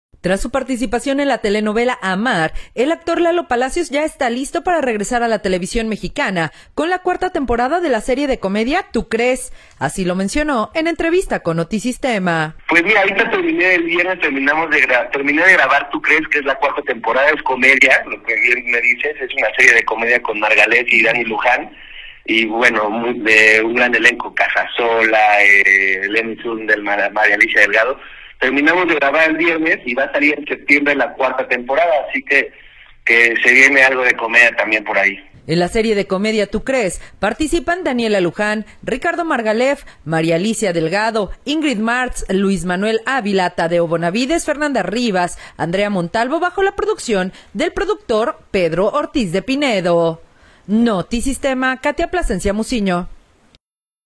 Tras su participación en la telenovela “A.Mar”, el actor Lalo Palacios ya está listo para regresar a la televisión mexicana con la cuarta temporada de la serie de comedia “Tú crees”, así lo mencionó en entrevista con Notisistema.